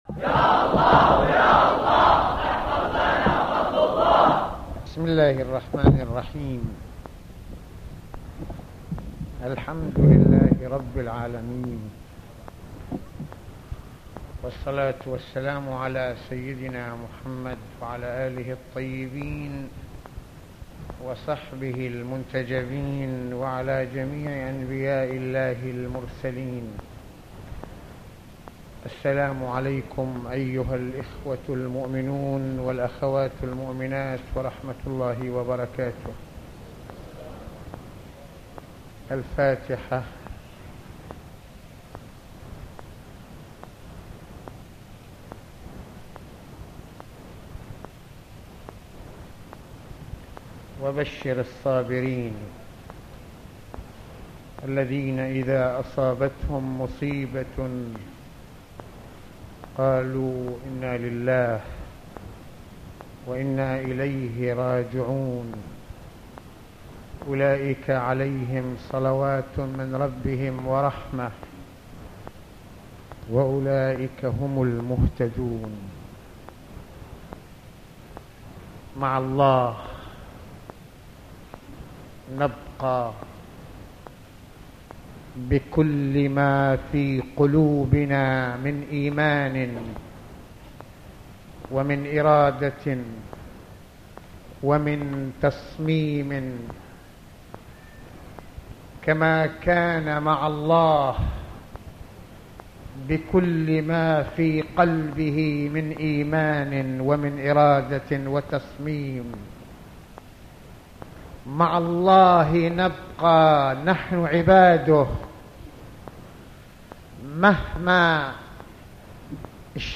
إن رحل الإمام الخمينيّ فنهجه باق | أرشيف خطب الجمعة عام 1989